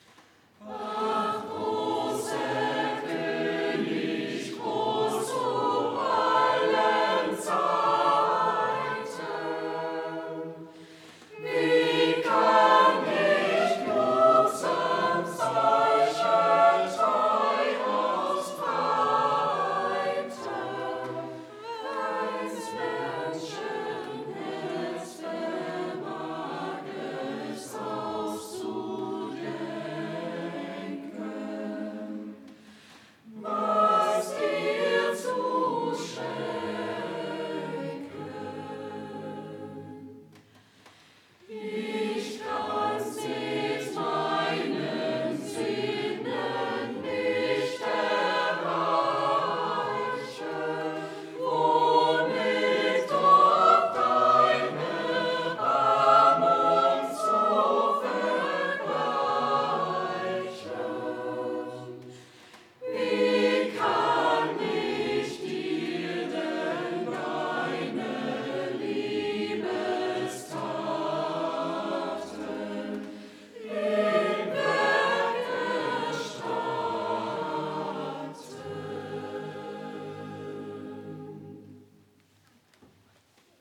Ach, großer König Chor der Ev.-Luth. St. Johannesgemeinde Zwickau-Planitz
Audiomitschnitt unseres Gottesdienstes vom Palmsonntag 2026.